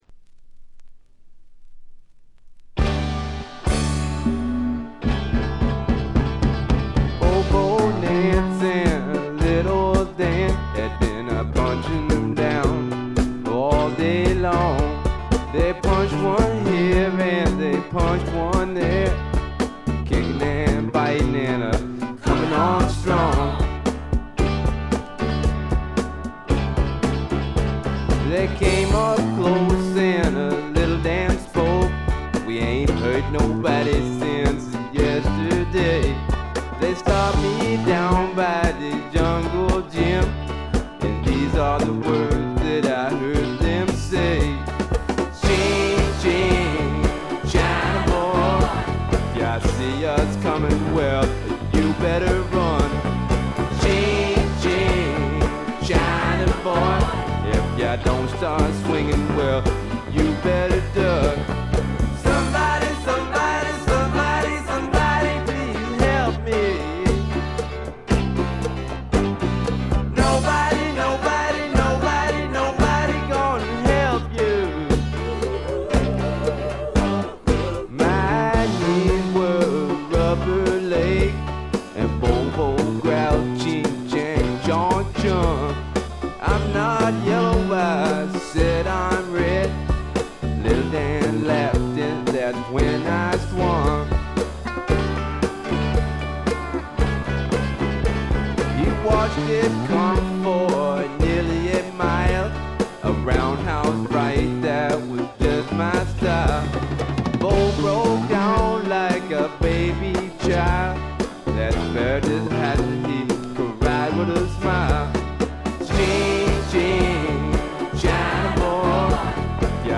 ほとんどノイズ感無し。
よりファンキーに、よりダーティーにきめていて文句無し！
試聴曲は現品からの取り込み音源です。